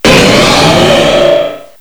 -Replaced the Gen. 1 to 3 cries with BW2 rips.
uncomp_mega_scizor.aif